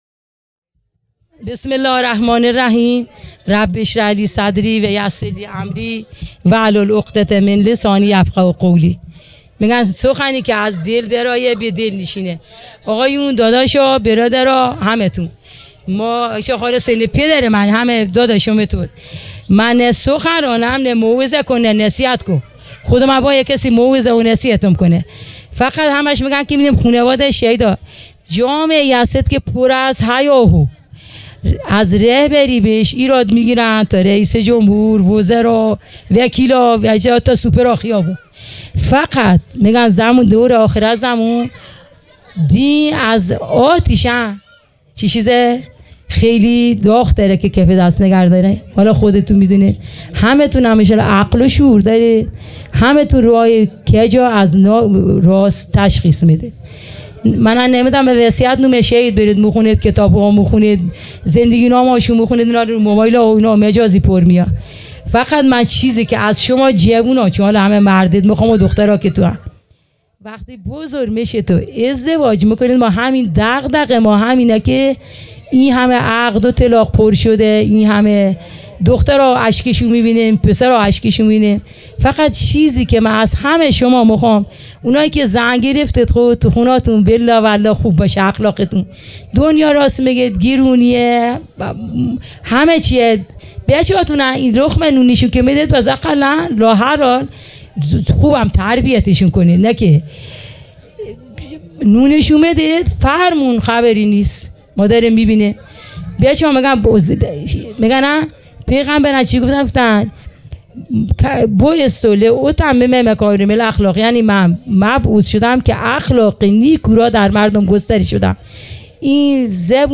خیمه گاه - هیئت بچه های فاطمه (س) - سخنرانی | ۶ مرداد ماه ۱۴۰۲